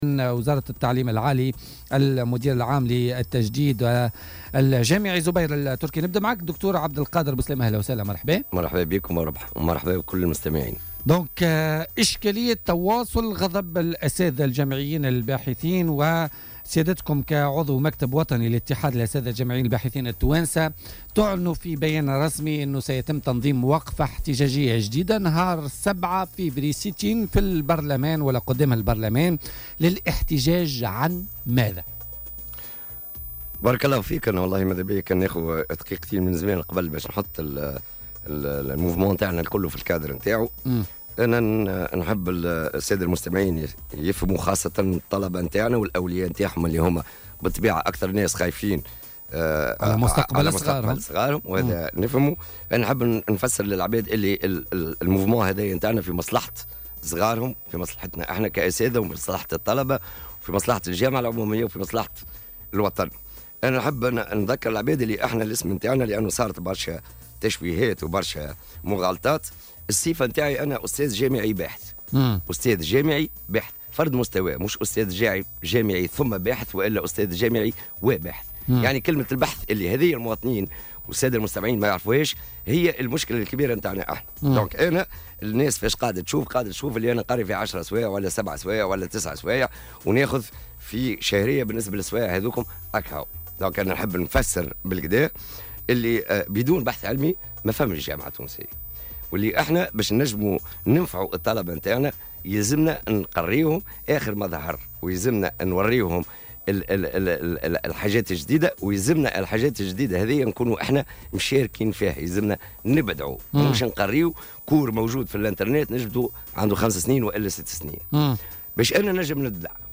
ضيف بوليتيكا